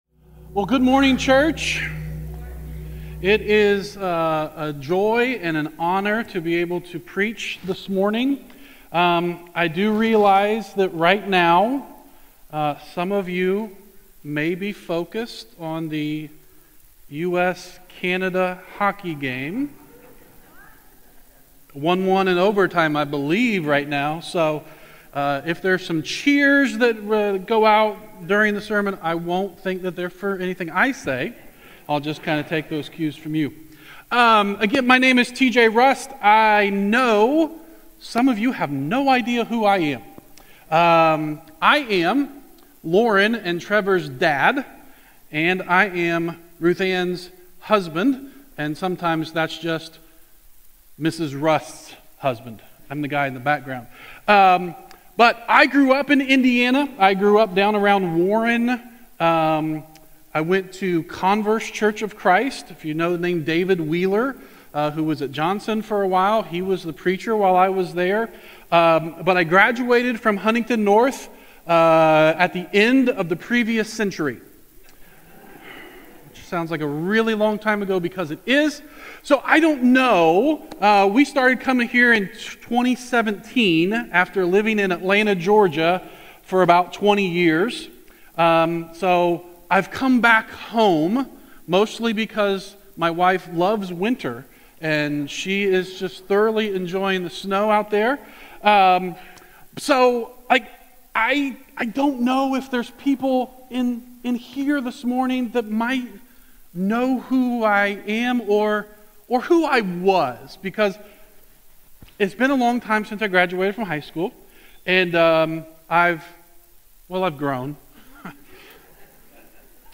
This podcast features the weekly audio messages given each Sunday morning.